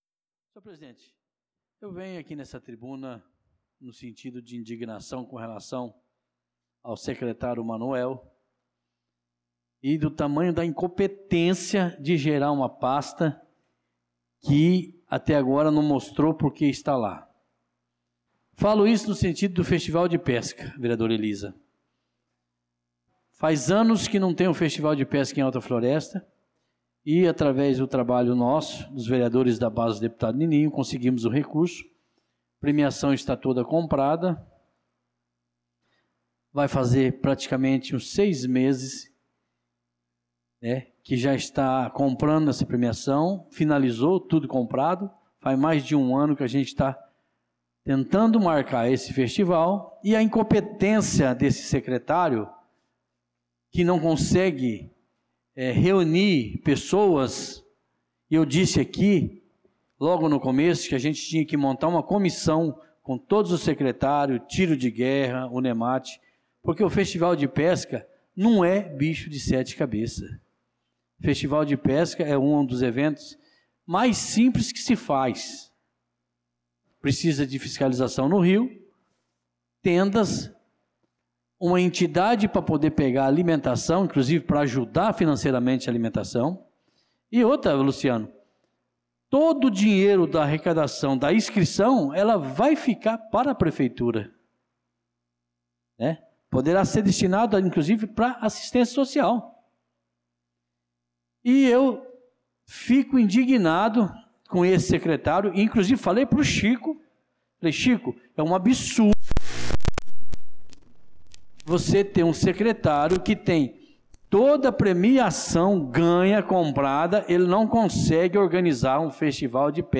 Pronunciamento do vereador Tuti na Sessão Ordinária do dia 23/06/2025